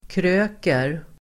Uttal: [kr'ö:ker]